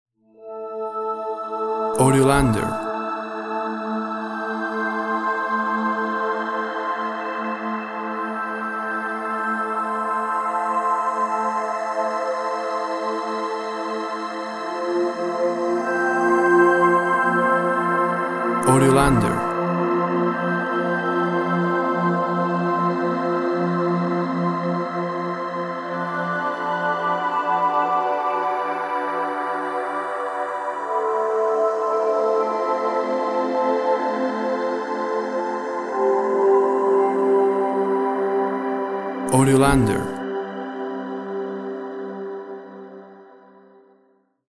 Synth instrumental with feeling of large spaces.
WAV Sample Rate 24-Bit Stereo, 44.1 kHz
Tempo (BPM) 60